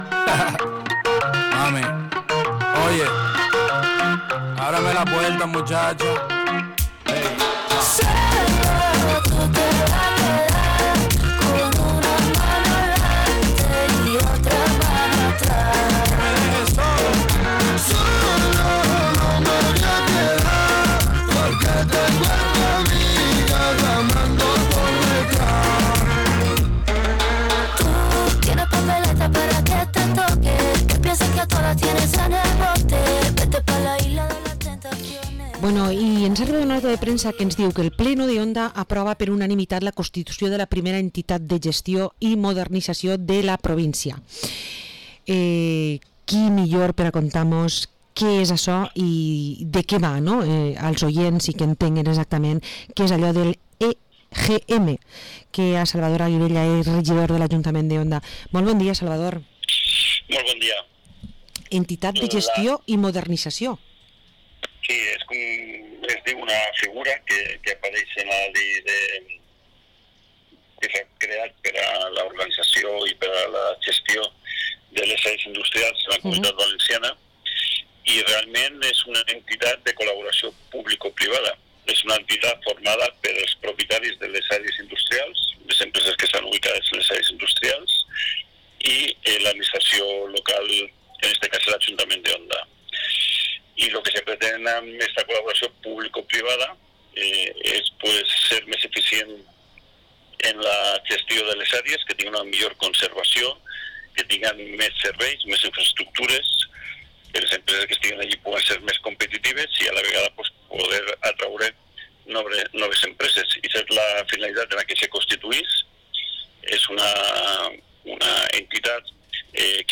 Entrevista al teniente alcalde de Onda, Salvador Aguilella